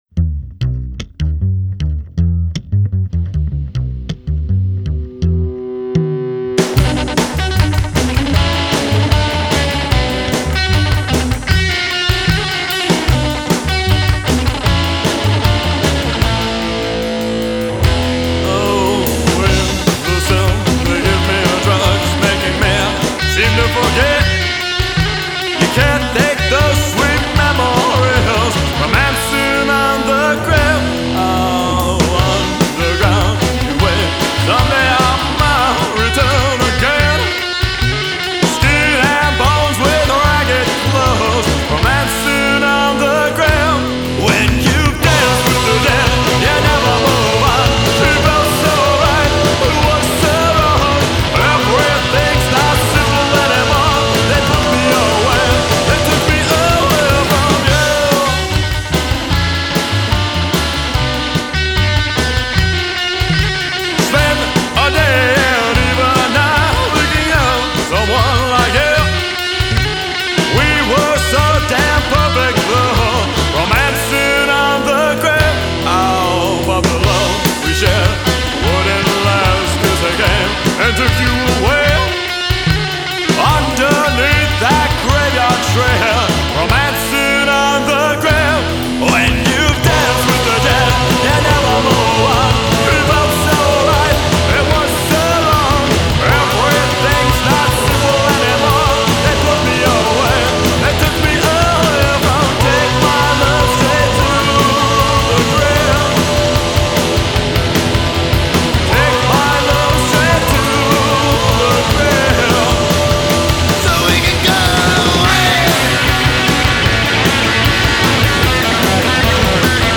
break-neck speed punk